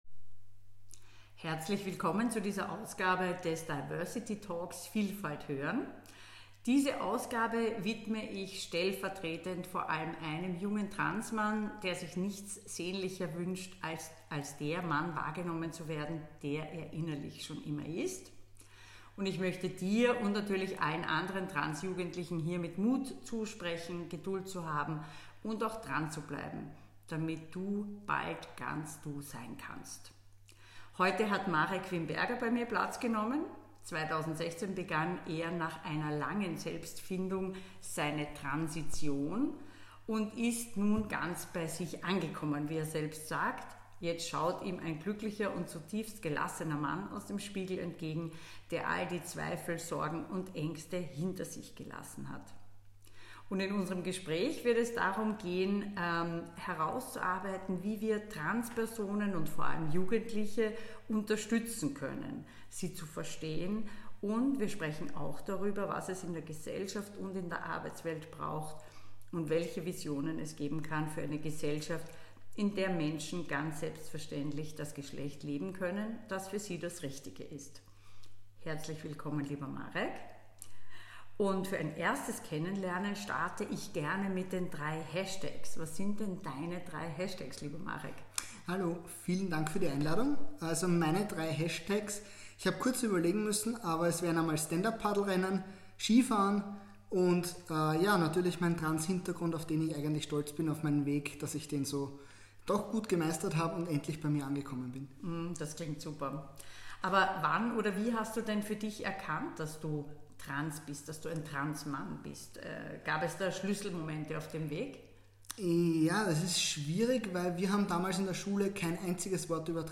Ich bin nicht mutig – ich bin einfach ich! Ein trans Mann im Gespräch.